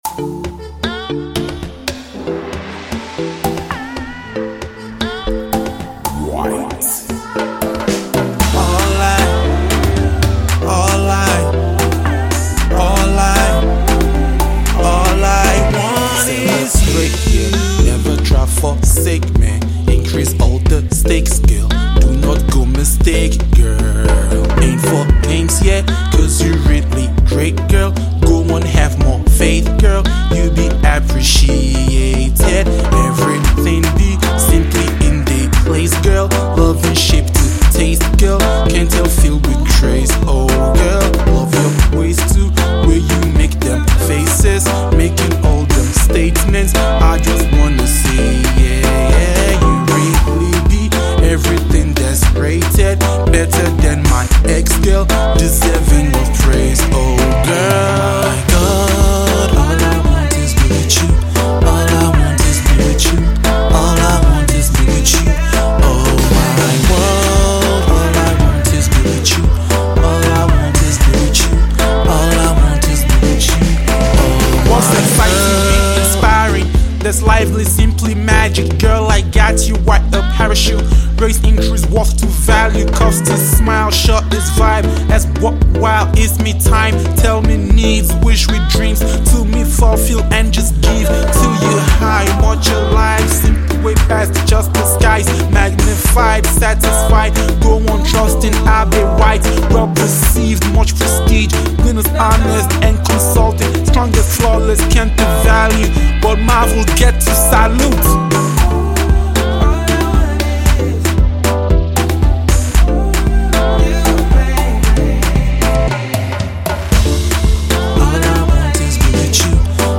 Fast rising artist singer
mid-tempo song